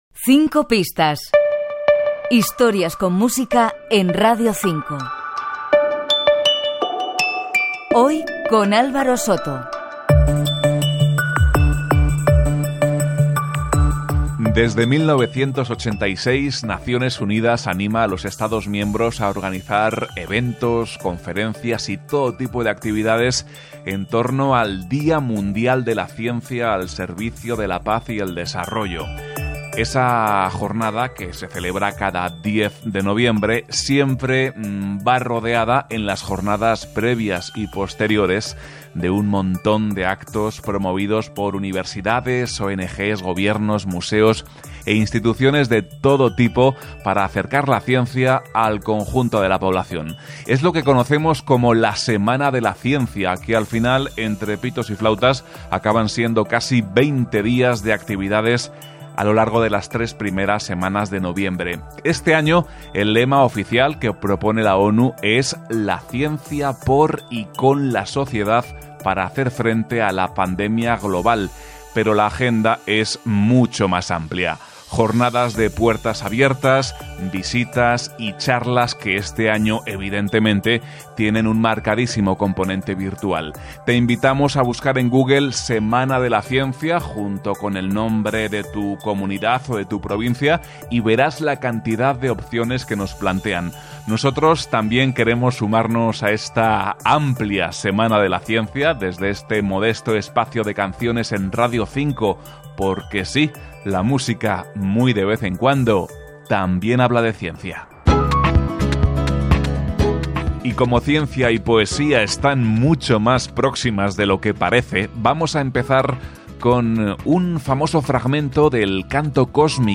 Careta del programa, la Setmana de la Ciència i un espai dedicat a la música i la ciència
Entreteniment